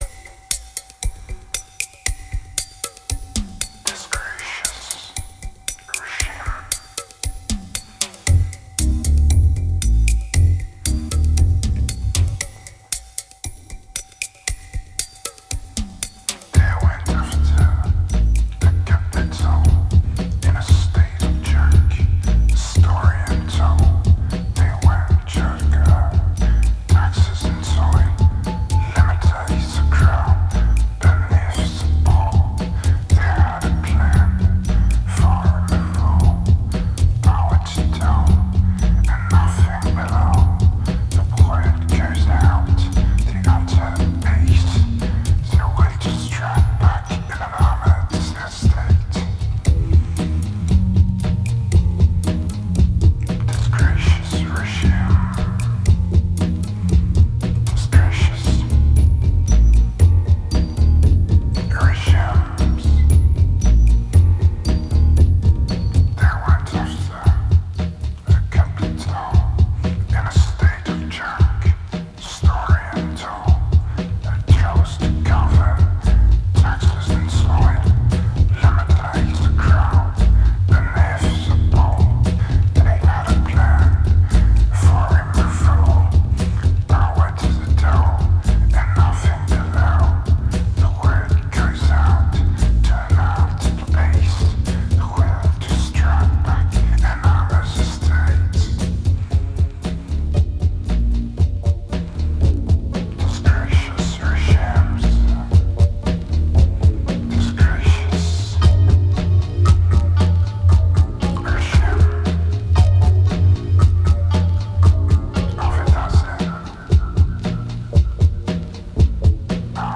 2318📈 - -12%🤔 - 116BPM🔊 - 2010-03-09📅 - -190🌟